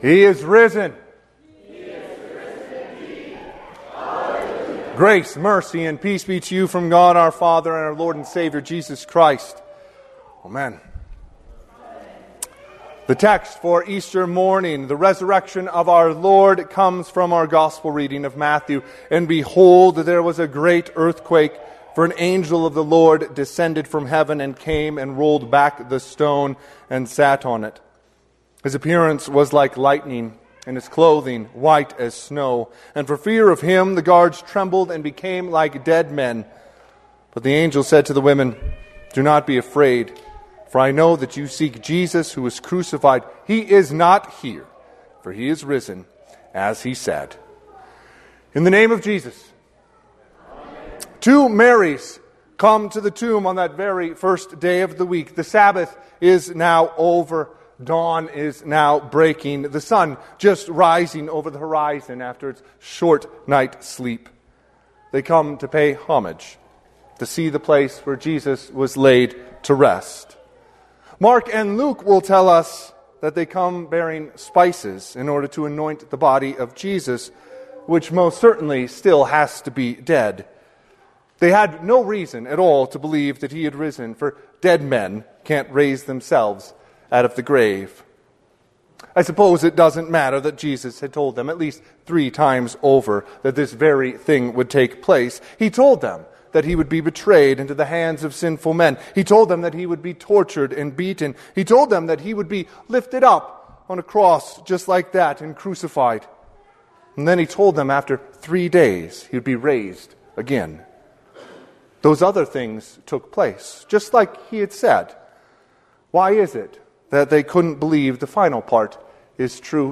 Sermon – 4/5/2026 - Wheat Ridge Evangelical Lutheran Church, Wheat Ridge, Colorado